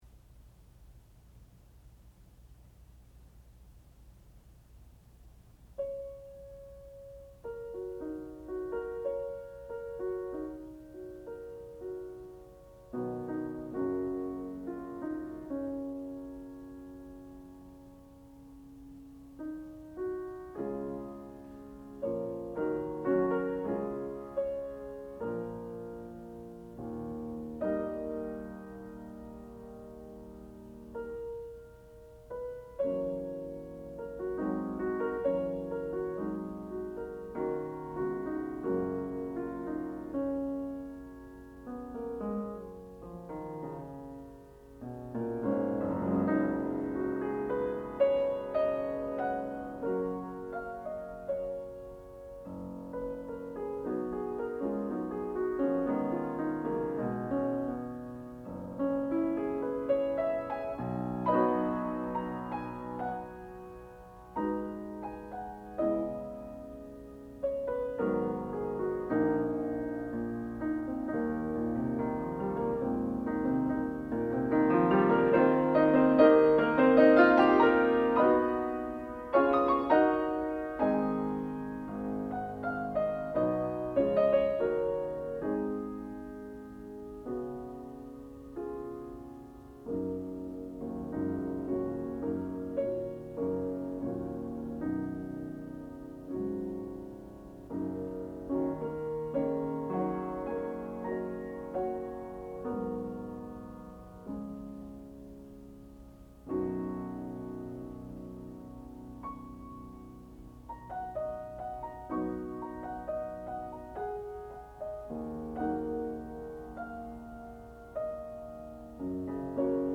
sound recording-musical
classical music
Junior Recital
piano